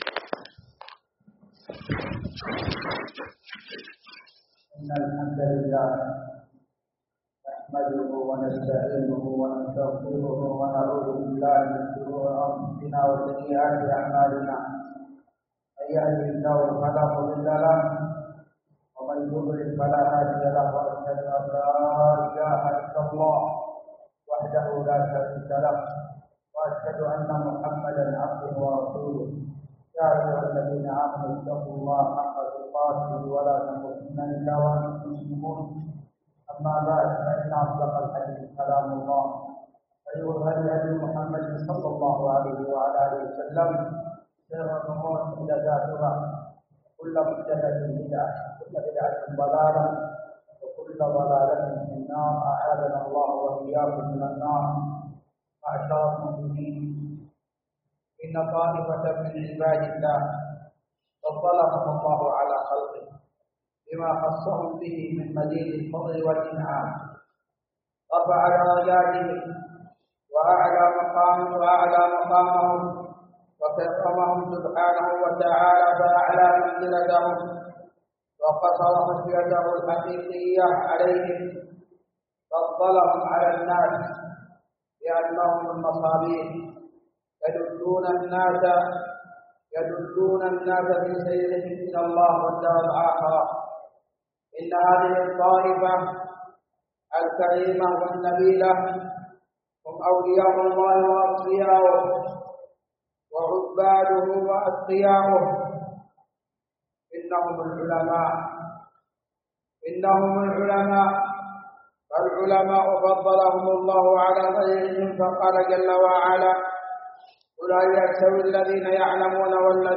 جامع الملك عبدالعزيز باسكان الخارش بصامطة
مواعظ ورقائق